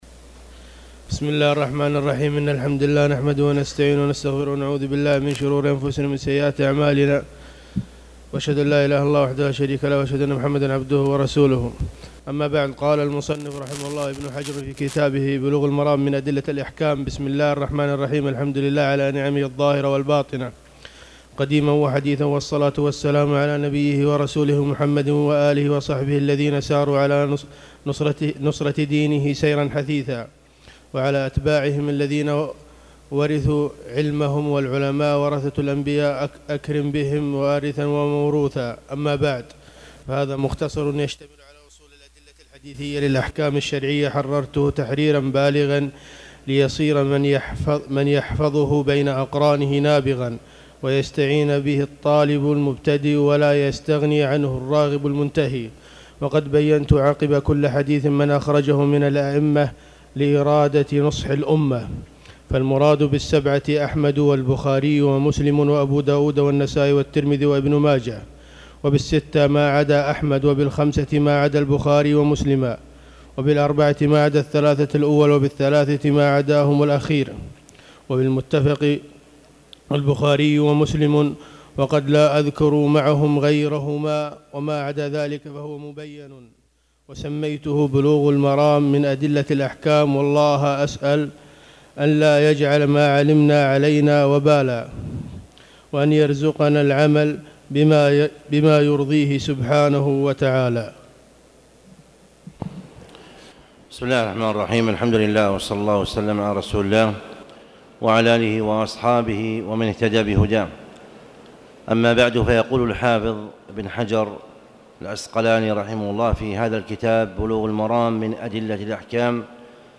تاريخ النشر ٢٢ ربيع الأول ١٤٣٨ هـ المكان: المسجد الحرام الشيخ